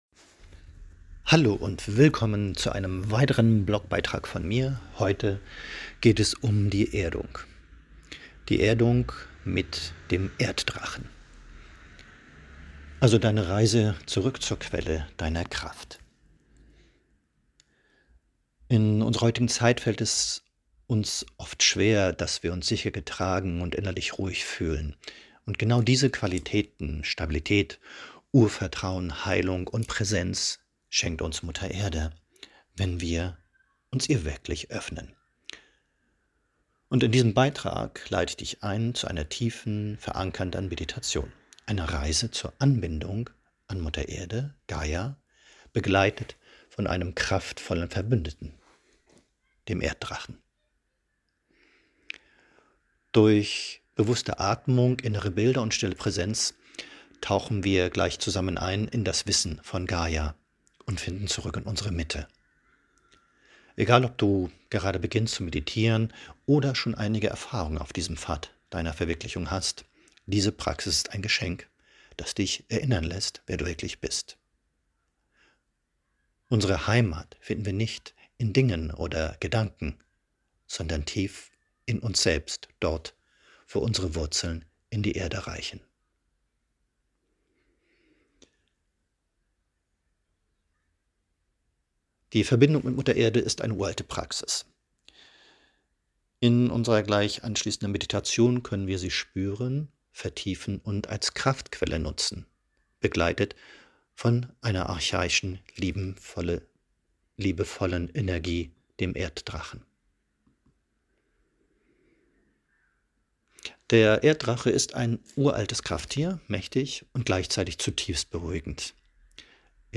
Die zweite Datei enthält die Anleitung zur Meditation in gesprochener Form.